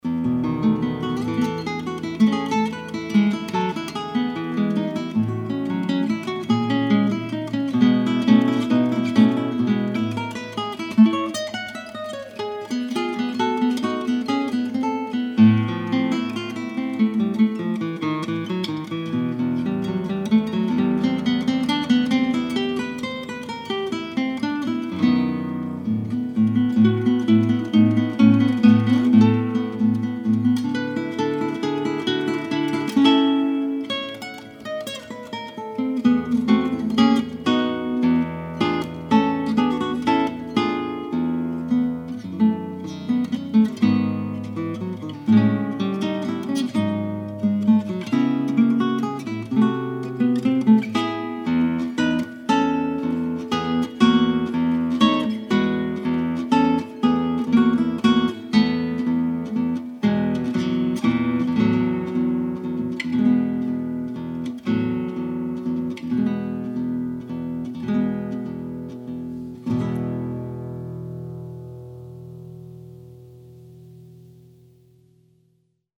It consists of a theme and seven variations.